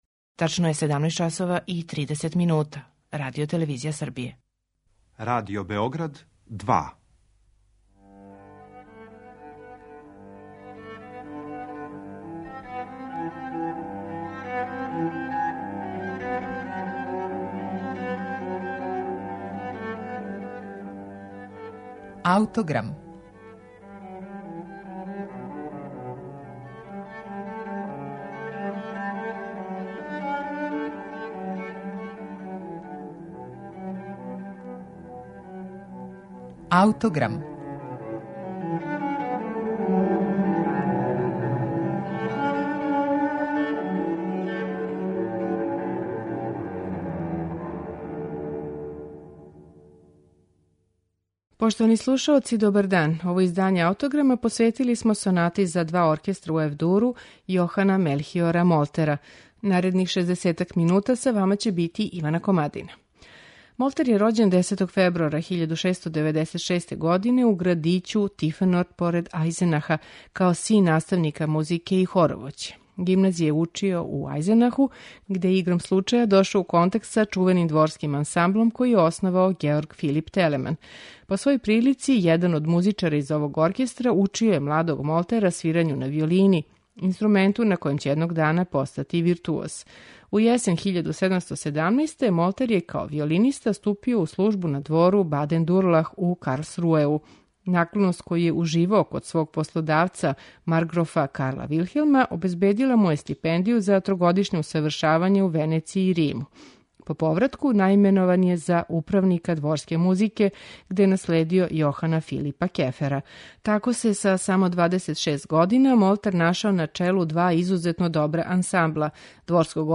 Она не објашњава само наслов става, већ и његов сасвим неуобичајени, спори темпо, што је преседан за све врсте вишеставачних композиција тог доба.